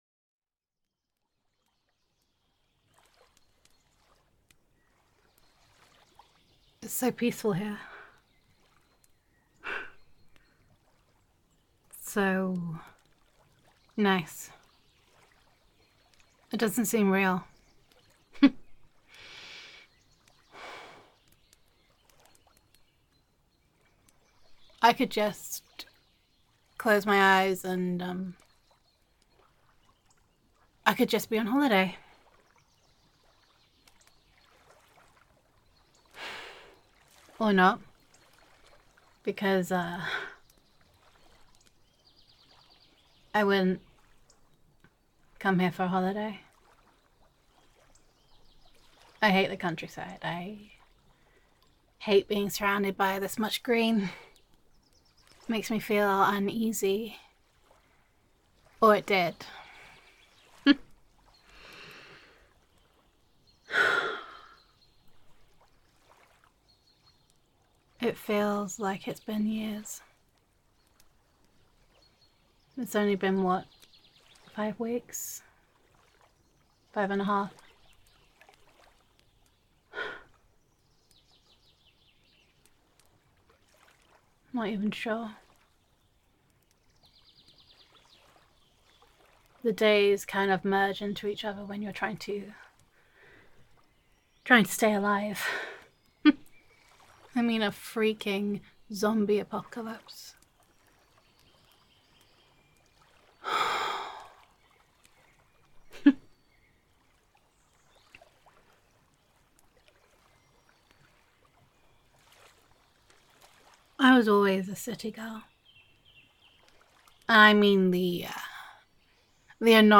Download [F4A] Just the Two of Us [Zombie Apocalypse][Sci FI][Lakeside Ambience][Awkward but Sweet][Friends to Lovers][Love Confession][Gender Neutral][Thankfully Horror Films and Fps Games Prepared Us for the Zombie Apocalypse].mp3